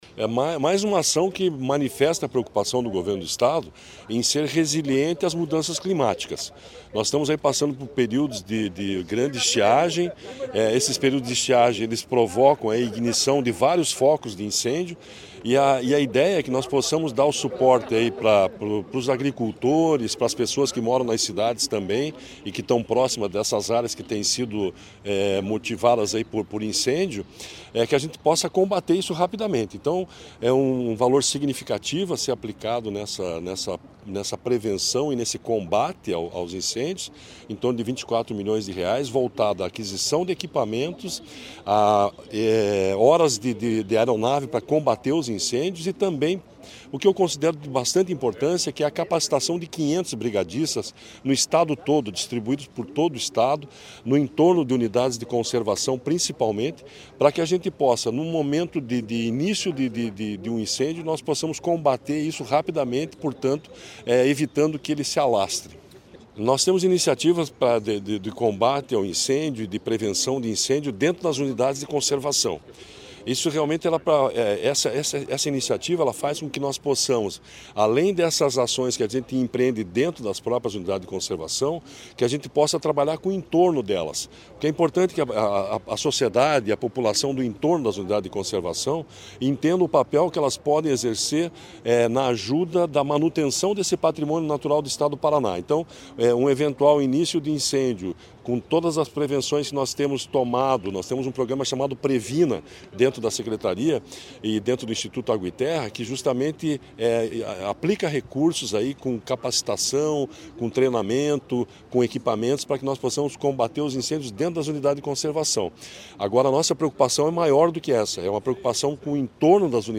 Sonora do secretário de Desenvolvimento Sustentável, Everton Souza, sobre as ações de combate a incêndios no Paraná